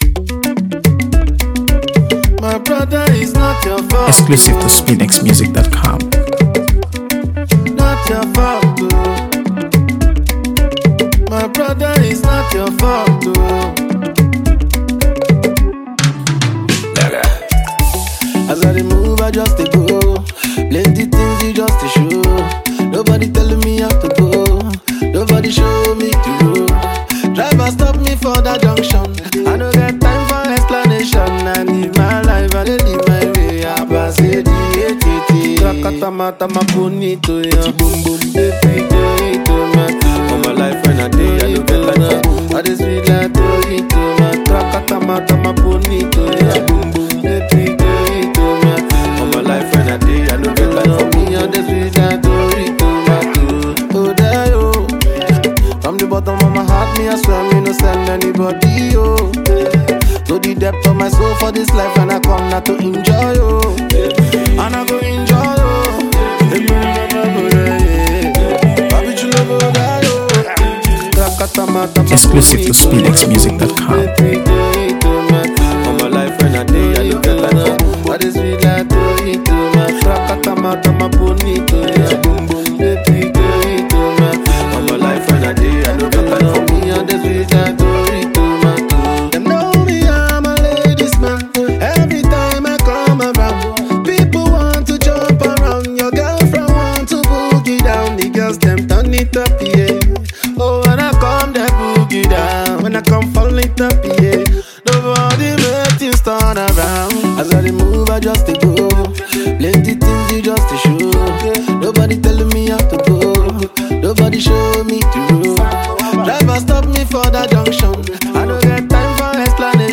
AfroBeats | AfroBeats songs
Groovy Single